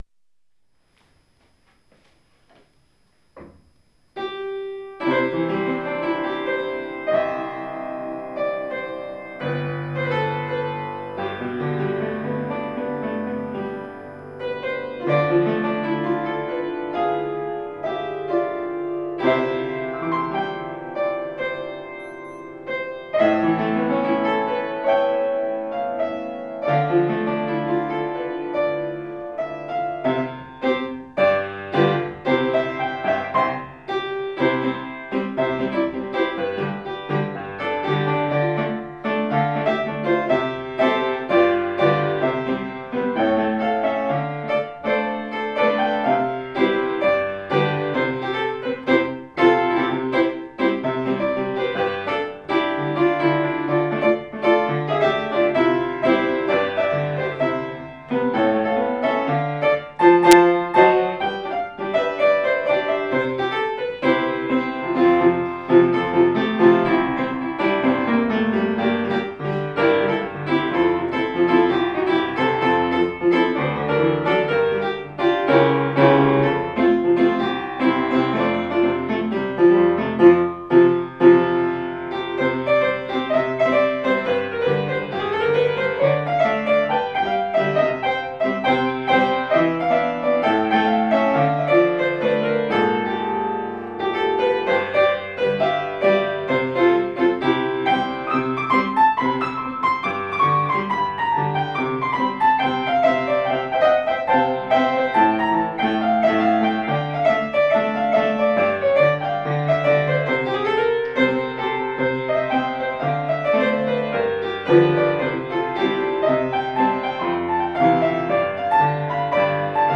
מארש צבאי